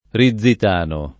Rizzitano [ ri zz it # no ] cogn.